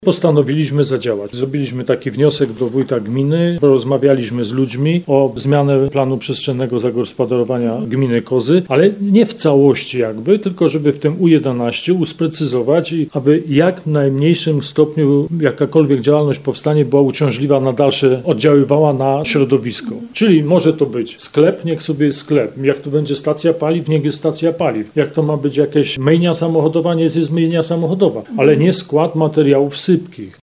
Z zastrzeżeniem, że te działalności, które już tam były prowadzone mogą zostać – tłumaczą mieszkańcy.